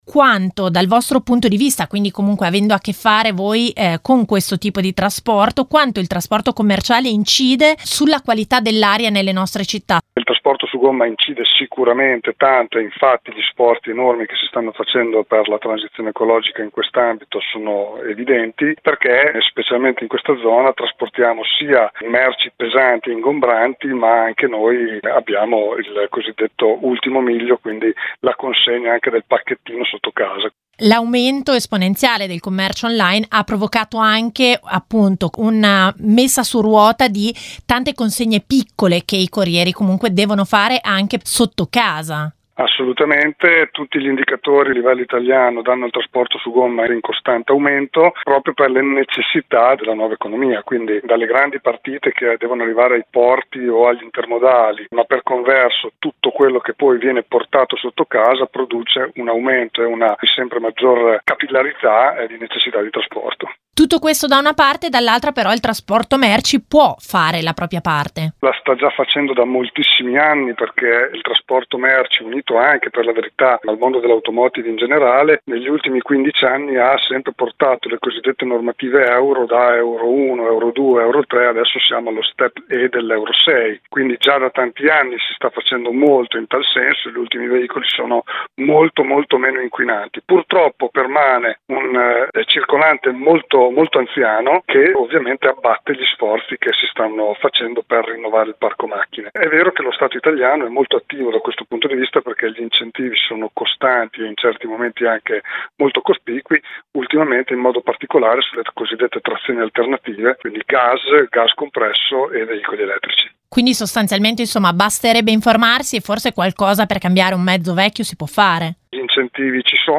Home Magazine Interviste Trasporto su gomma in aumento, a risentirne la qualità dell’aria